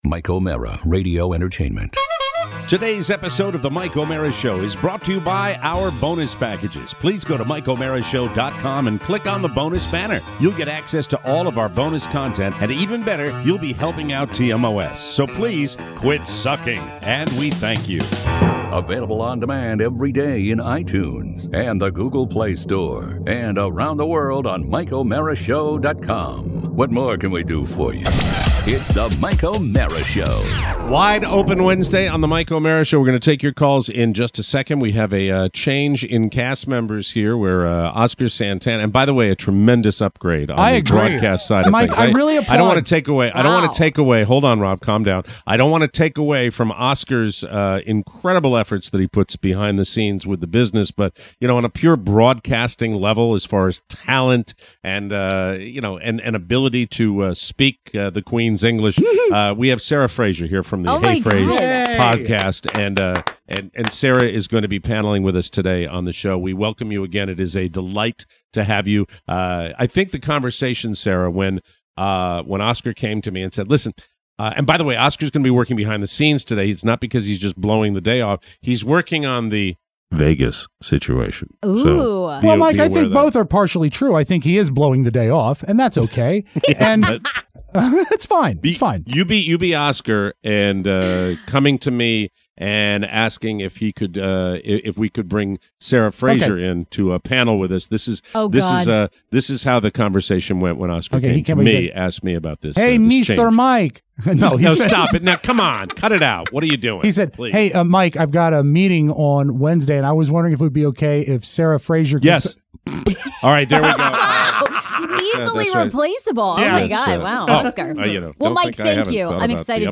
We take your calls, talk controversy, discuss truck names… and Rock the Red.